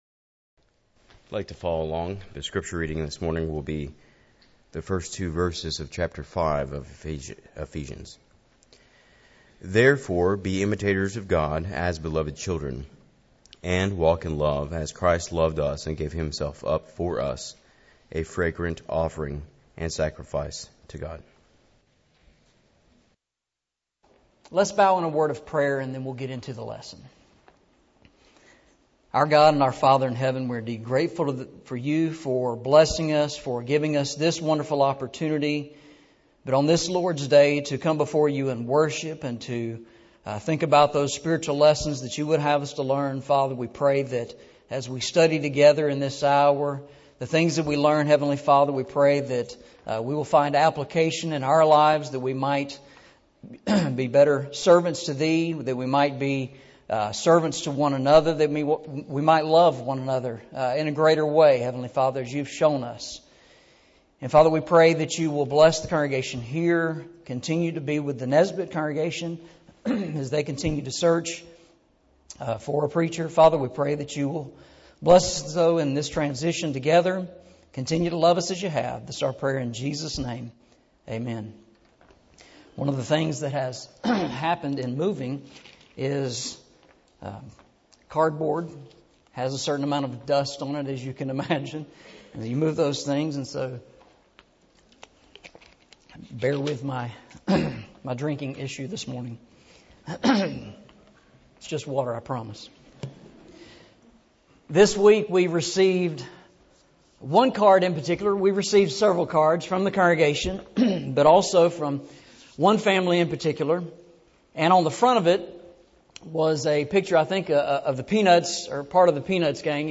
A SIMPLE Sermon